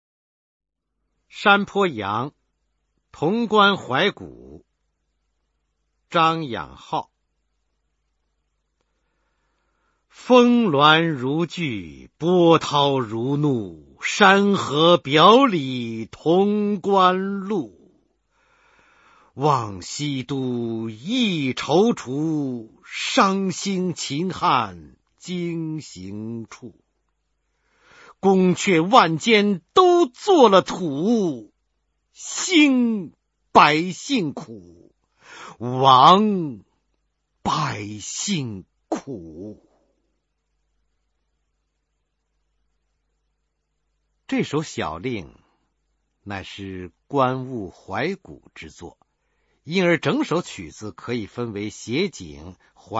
九年级语文下册24 诗词曲五首《山坡羊·潼关怀古》男声高清朗诵（音频素材）